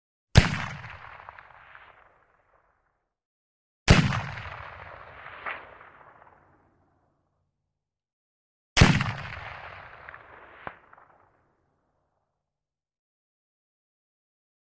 30.06 Rifle: Single Shots with Slap back ( 3x ); Three Boomy, Heavy Low End Shots. Huge Sounding With Long, Rolling Echo. Medium Close Perspective. Gunshots.